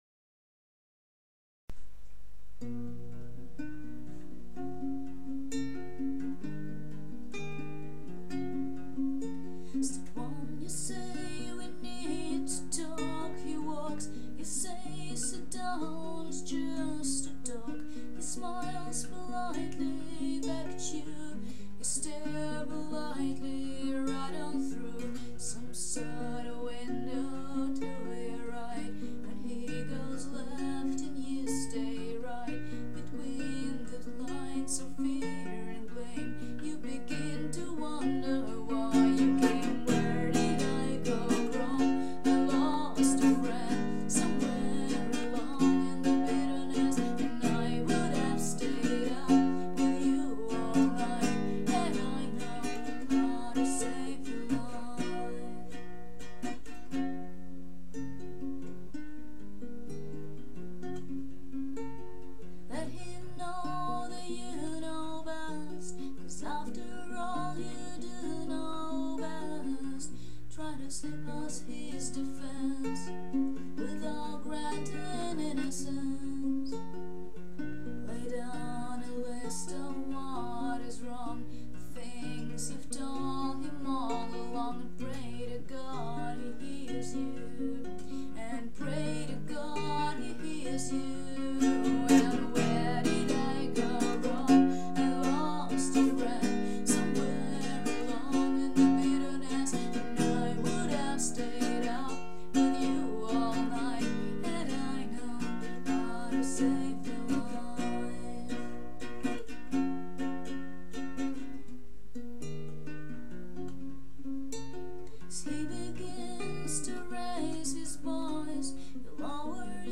acoustic version